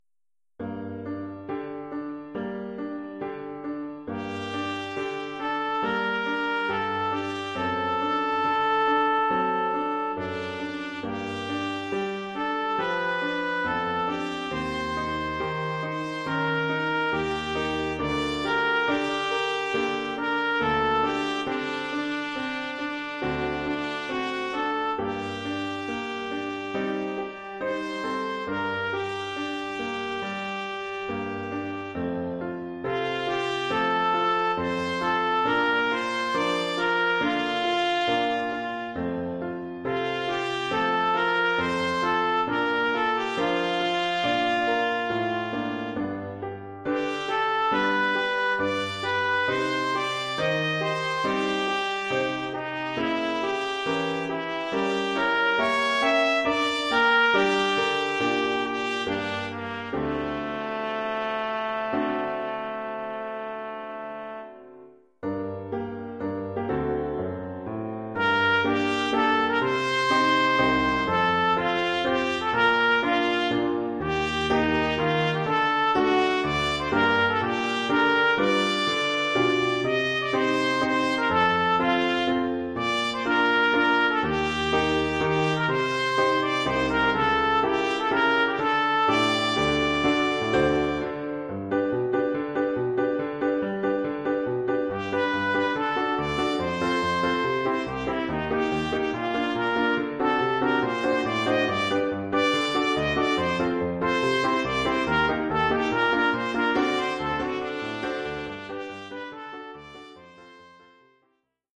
Oeuvre pour trompette sib ou ut ou cornet ou bugle et piano.